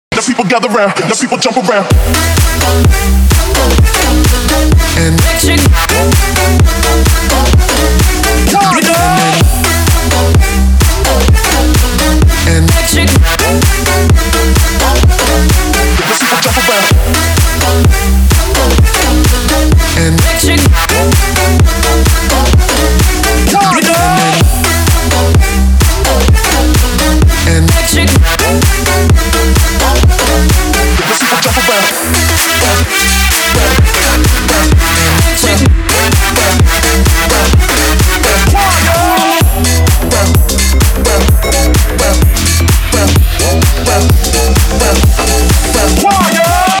веселые
Electronic
энергичные
быстрые
клубняк
house
Хороший клубный микс